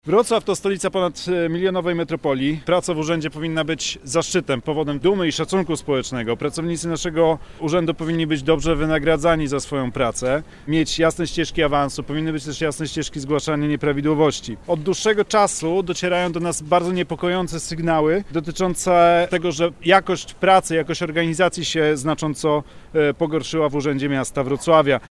– Mamy do czynienia z upadkiem etosu pracowników samorządowych – mówił podczas briefingu radny miejski Wrocławia, Piotr Uhle.